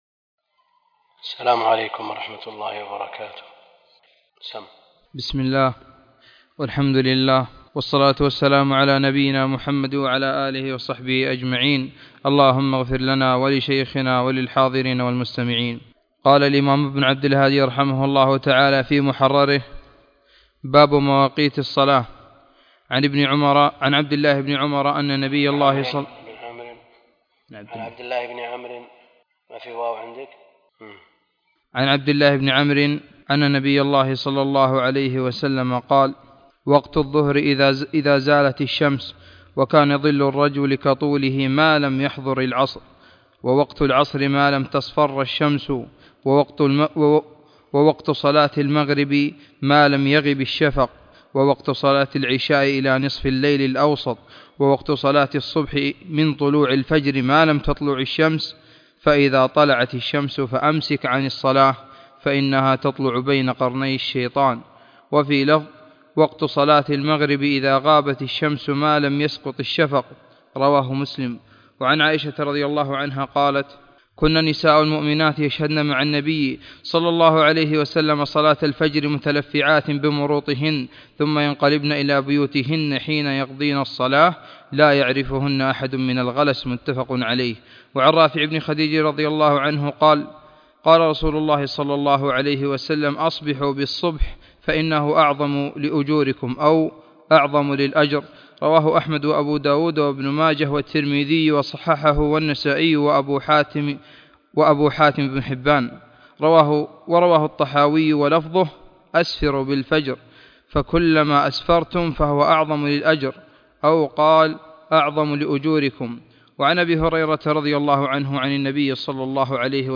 عنوان المادة الدرس (2) كتاب الصلاة من المحرر في الحديث تاريخ التحميل الأحد 7 يناير 2024 مـ حجم المادة 26.21 ميجا بايت عدد الزيارات 156 زيارة عدد مرات الحفظ 90 مرة إستماع المادة حفظ المادة اضف تعليقك أرسل لصديق